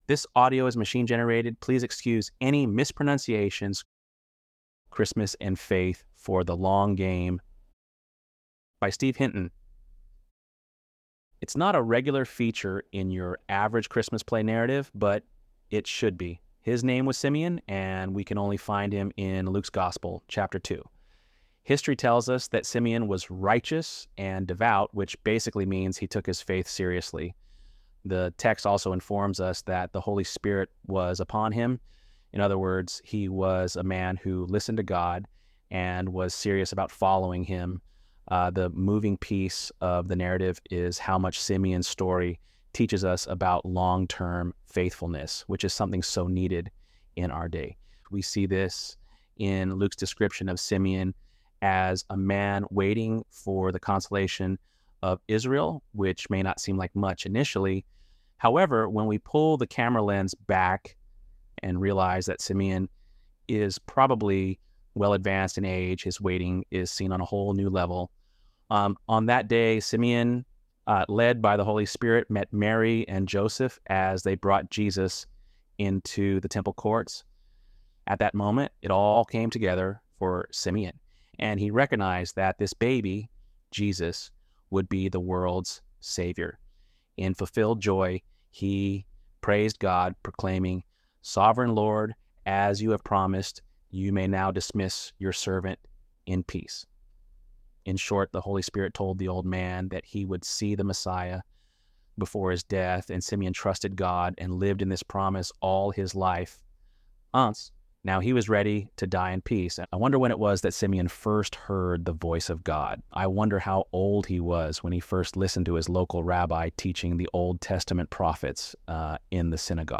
ElevenLabs_12_19.mp3